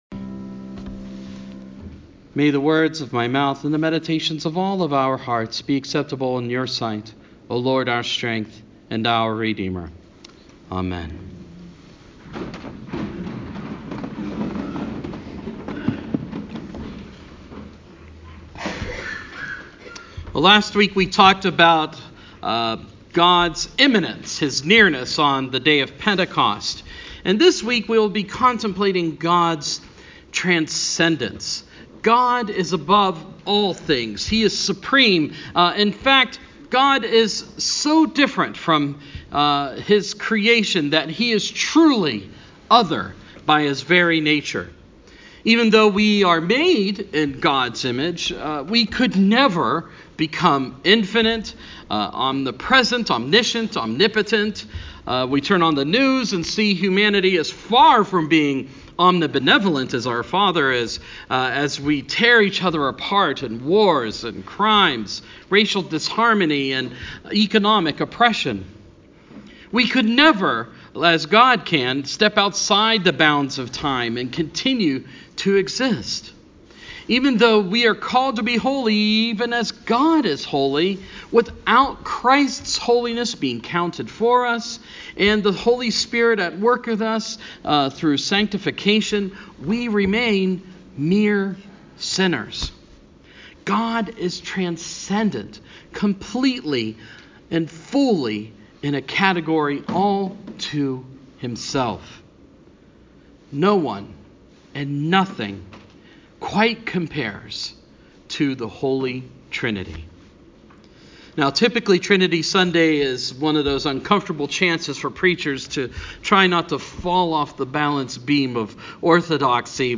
Sermon – Trinity Sunday